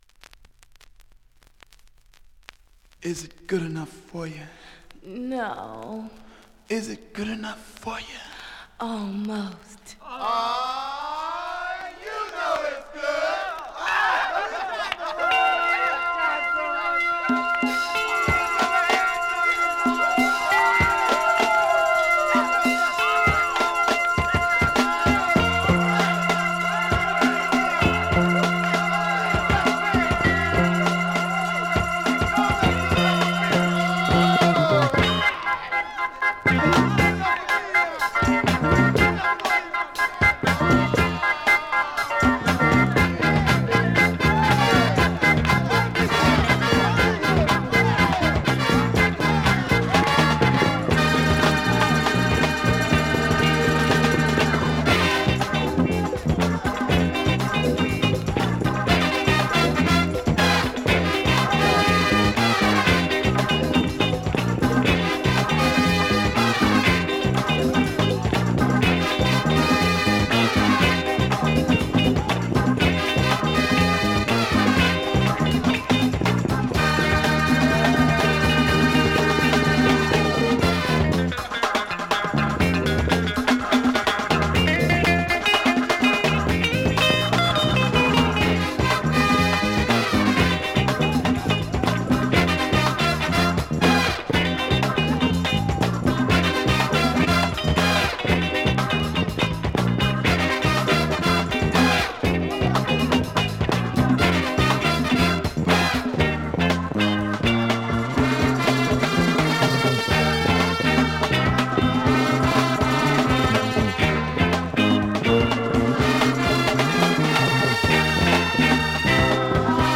現物の試聴（両面すべて録音時間７分１９秒）できます。
(Instrumental)
(Vocal)